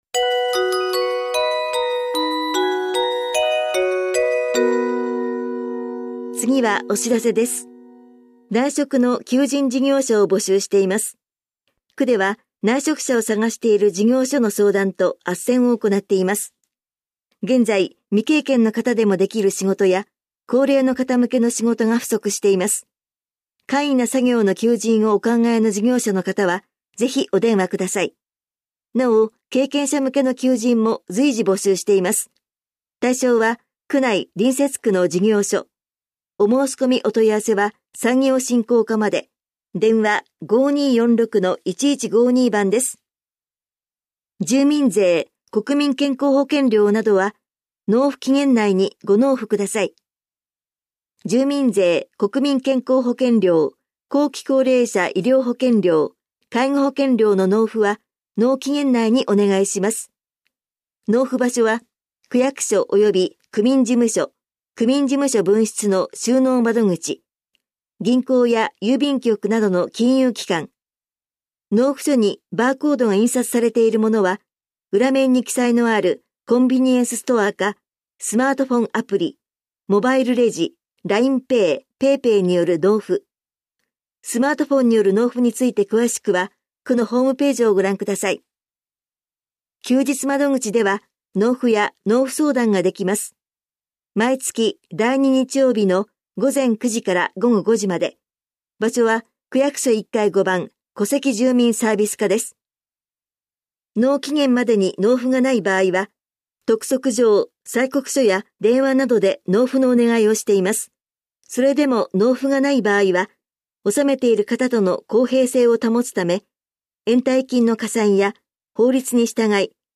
広報「たいとう」令和3年8月20日号の音声読み上げデータです。